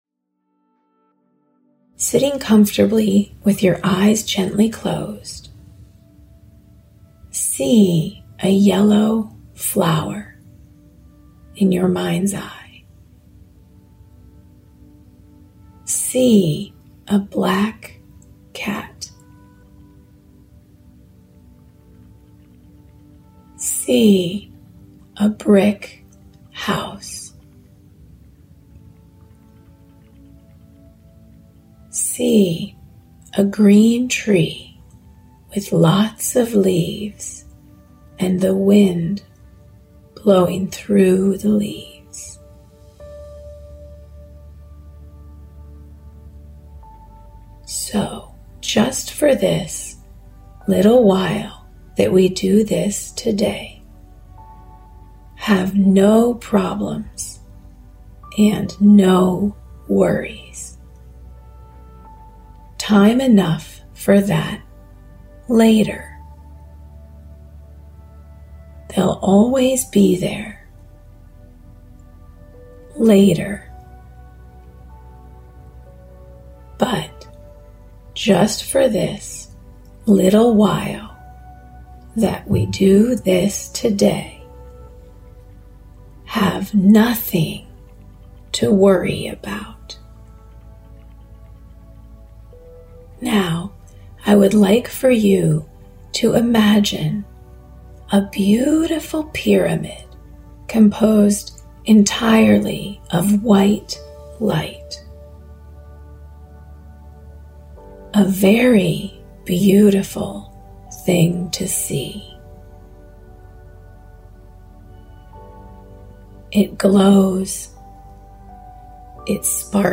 30 Day Meditation Challenge